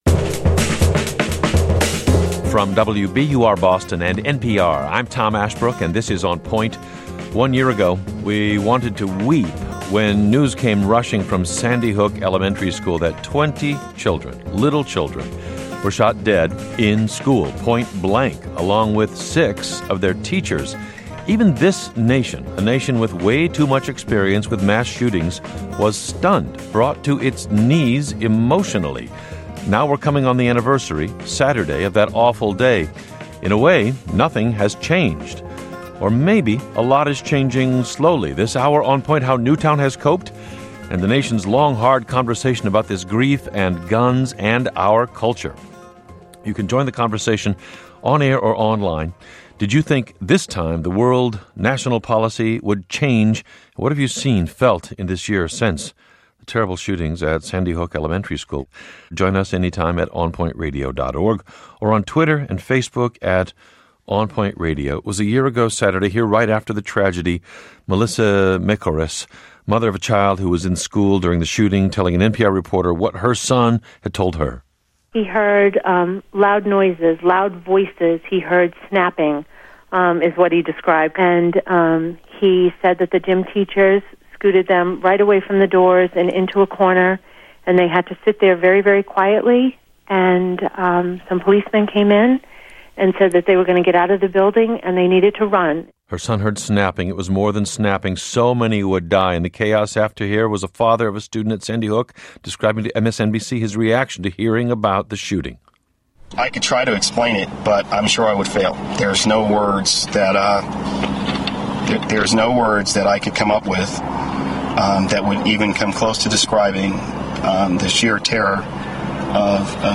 panel discussion